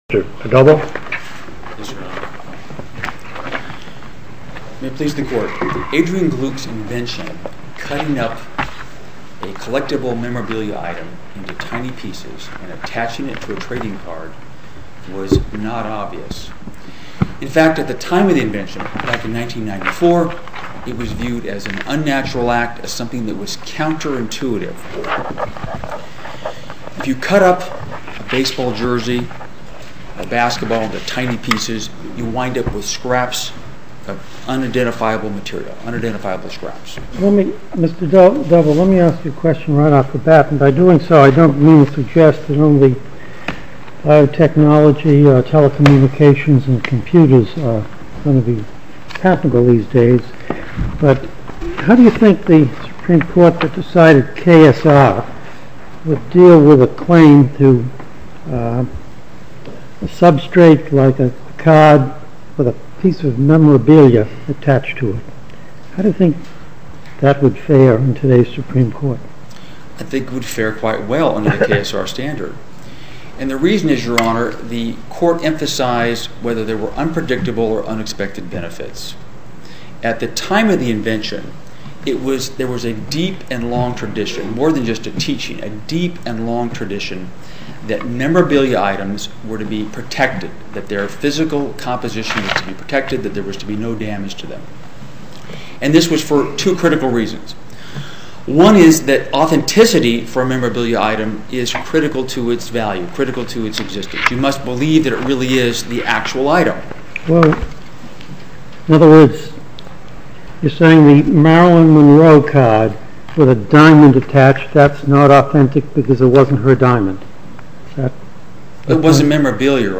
Oral argument audio posted: Media Technologies v Upper Deck (mp3) Appeal Number: 2009-1022 To listen to more oral argument recordings, follow this link: Listen To Oral Arguments.